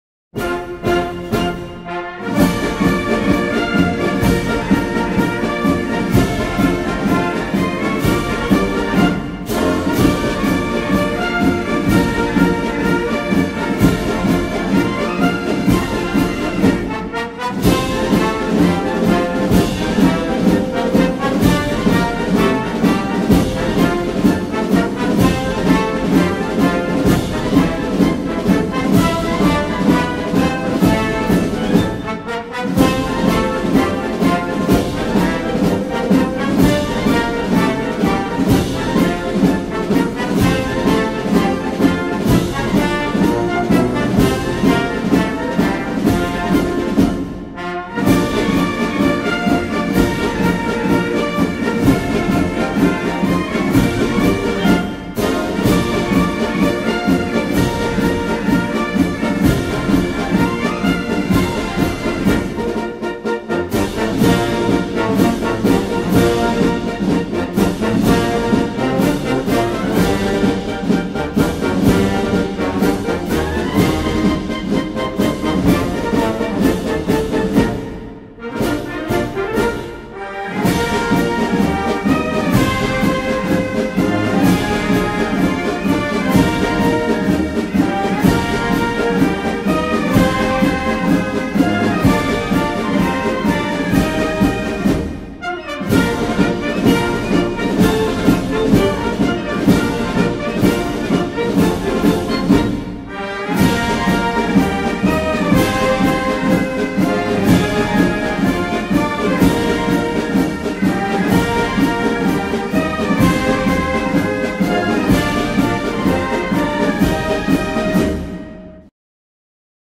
Hay que visionar las fotografías con esta marcha militar,